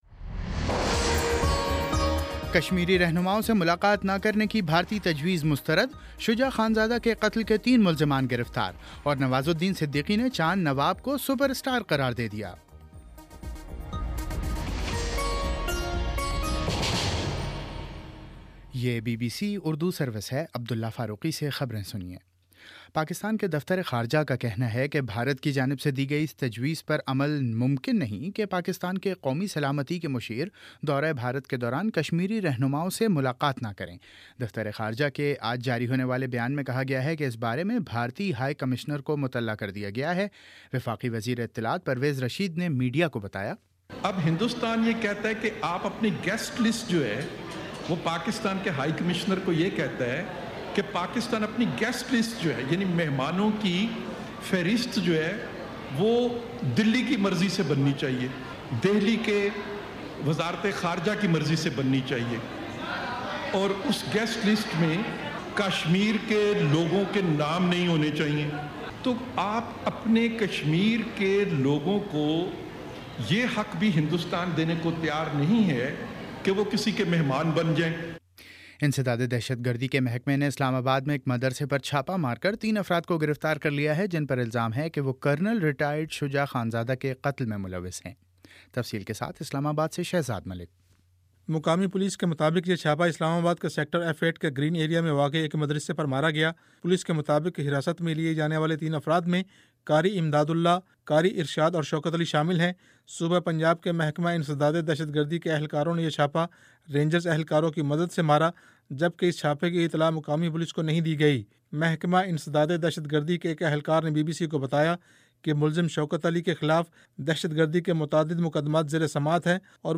اگست 21: شام پانچ بجے کا نیوز بُلیٹن